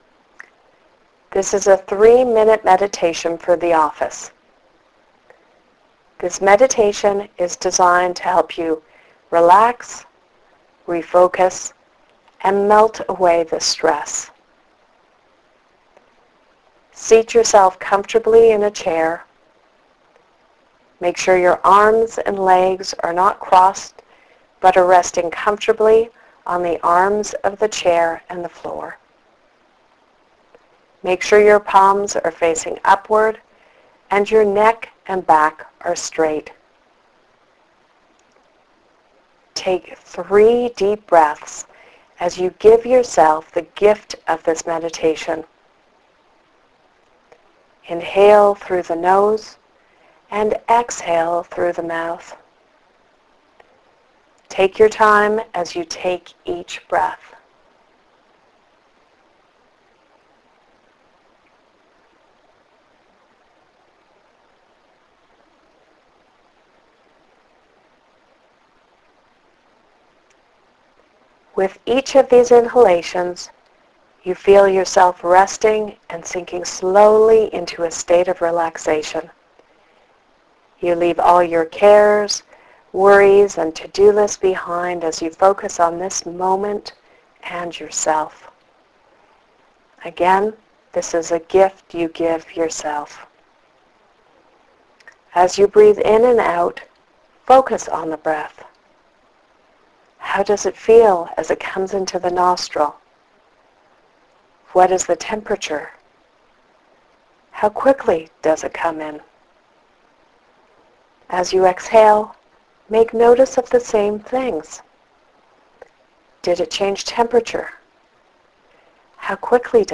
3 minutes Guided Meditation for time crunched individuals.